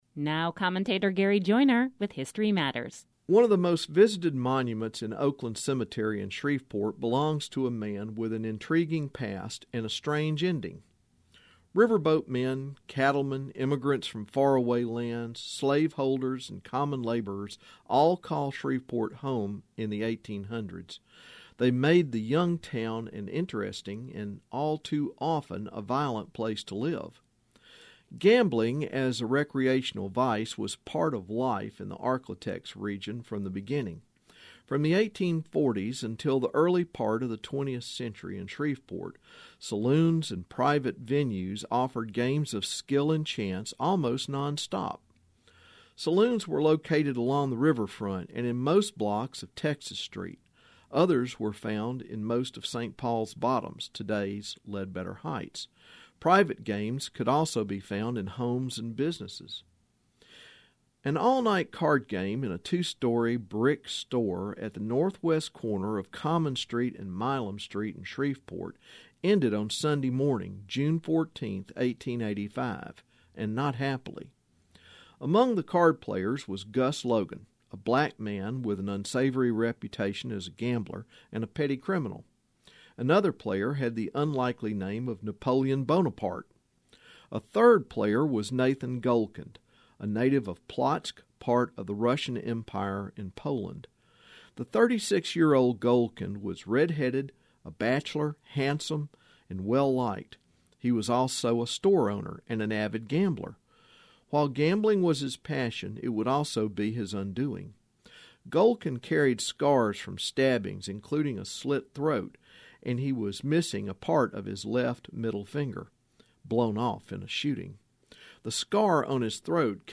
History Matters #422 - Nathan Goldkind commentary